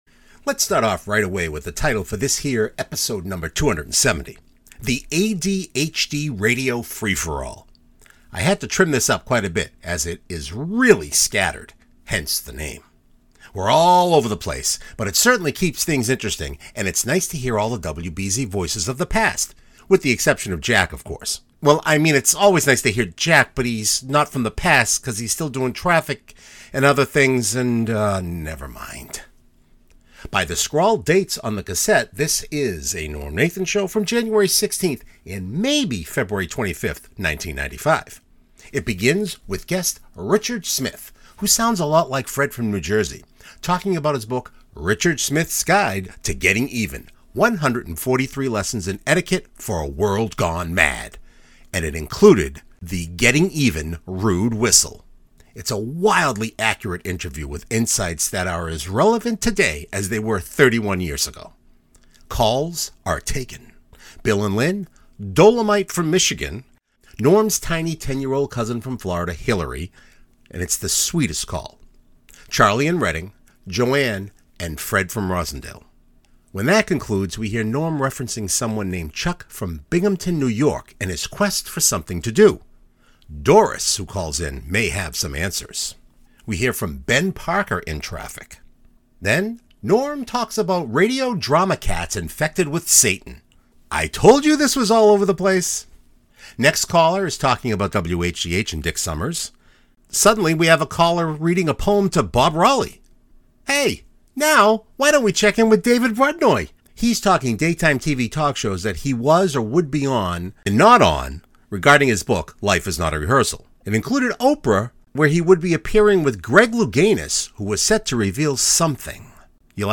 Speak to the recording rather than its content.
By the scrawled dates on the cassette this is a NNS from January 16th and maybe February 25th, 1995.